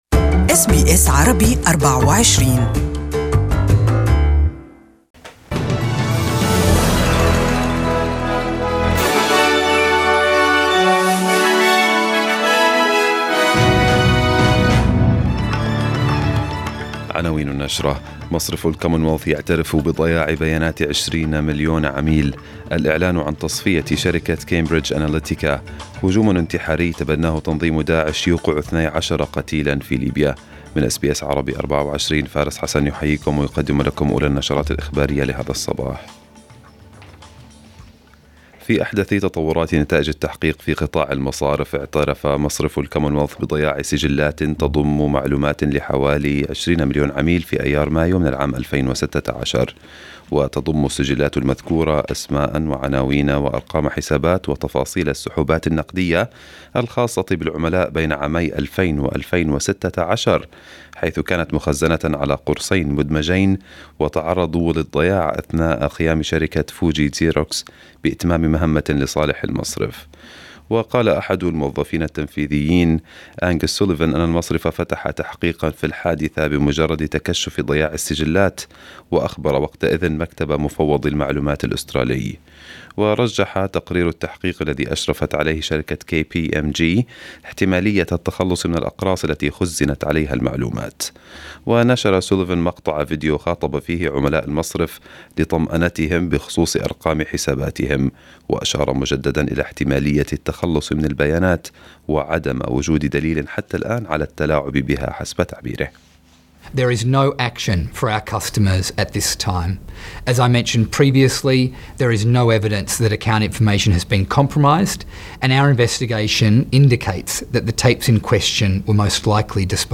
Arabic News Bulletin 03/05/2018